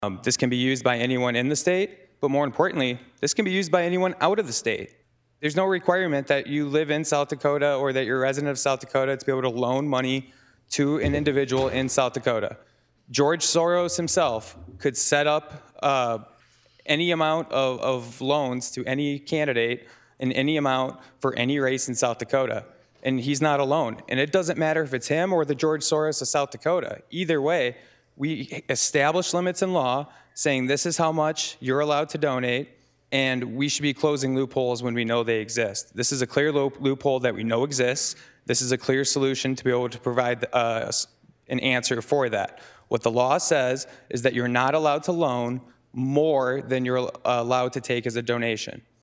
On the Senate floor, Thursday Senator Rohl discuss the purpose of the bill to the full Senate.